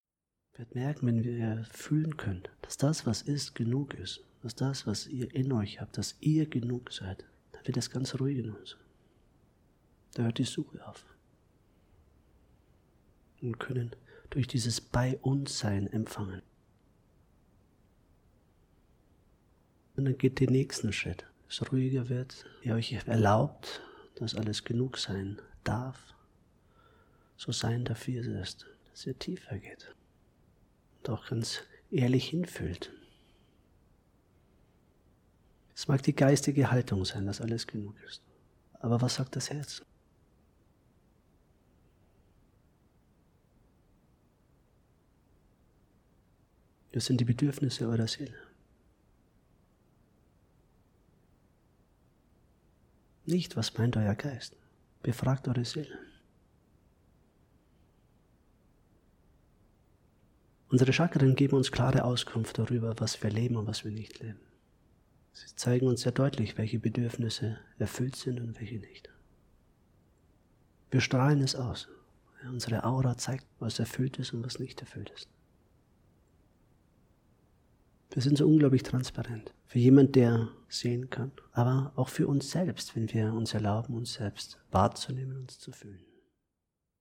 Meditation Live-Aufnahmen
Hörprobe_Chakren-Meditation.mp3